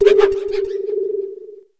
rabsca_ambient.ogg